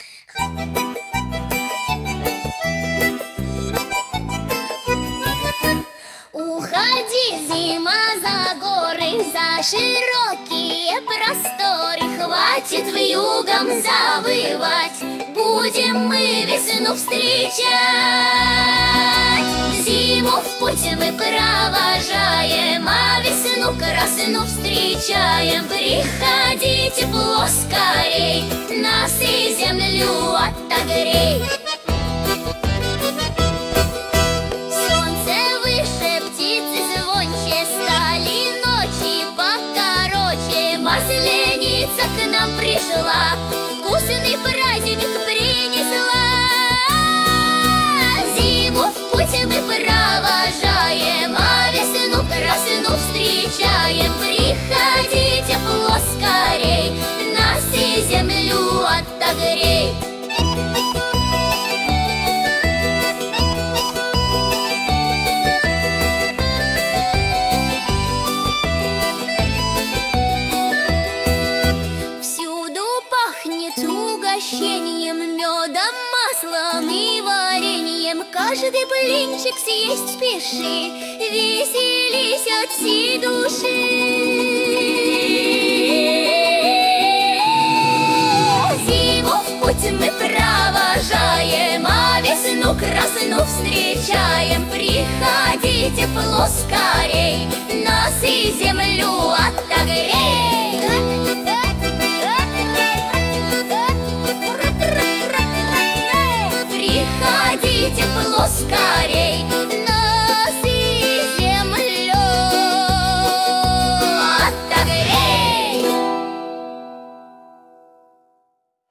• Качество: Хорошее
• Жанр: Детские песни
народный мотив